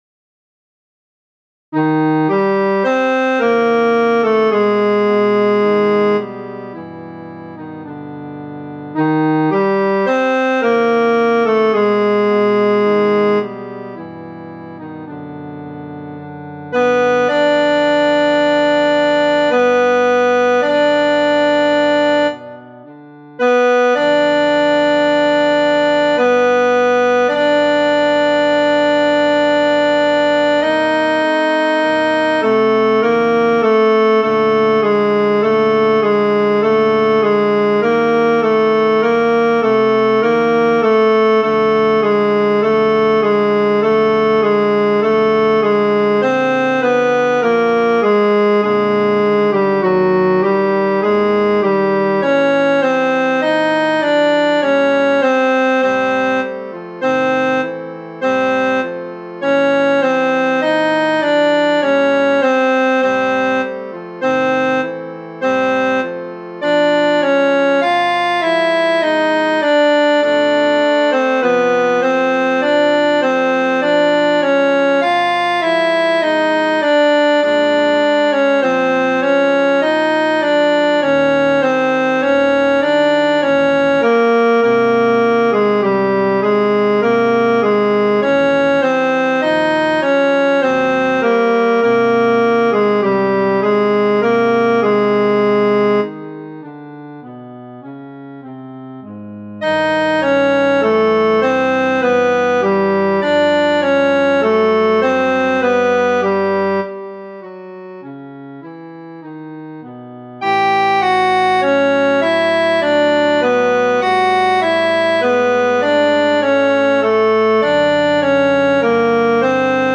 FF:HV_15b Collegium male choir
Zarlivec-T2.mp3